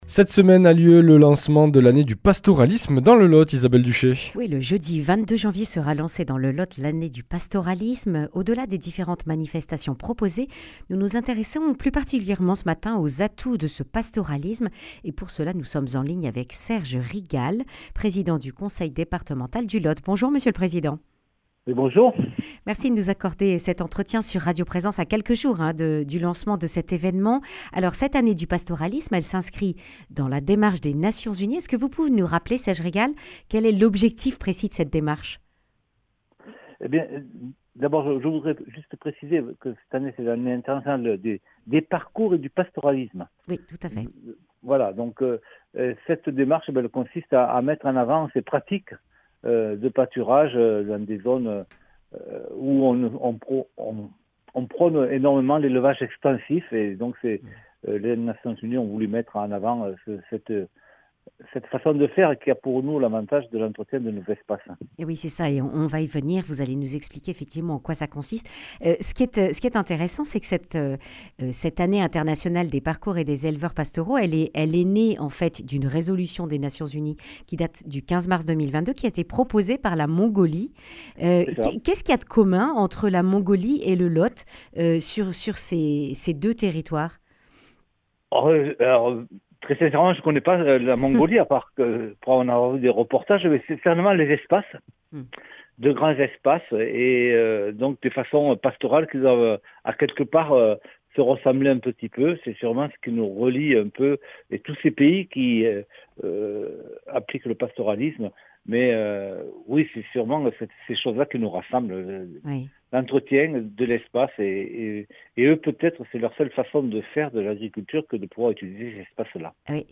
Le 22 janvier, l’année des parcours et éleveurs pastoraux est lancée dans le Lot. Serge Rigal, le président du Conseil départemental présente les atouts du pastoralisme.